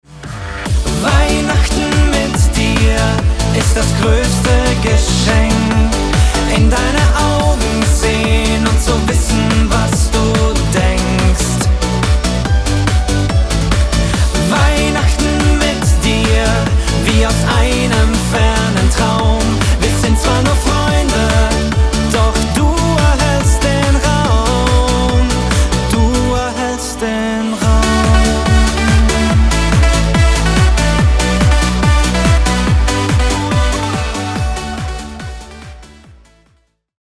jetzt als energiegeladene Party-Version